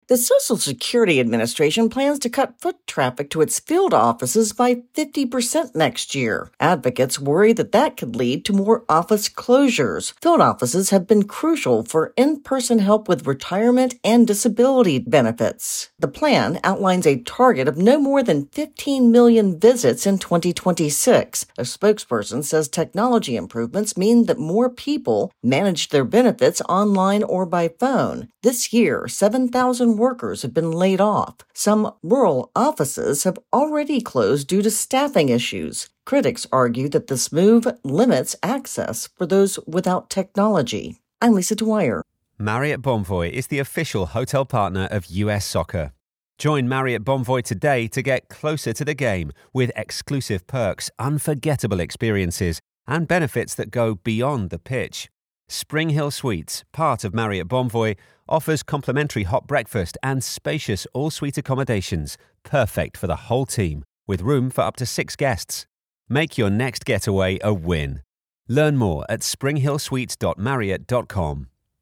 reports on plans to cut in person Social Security visits.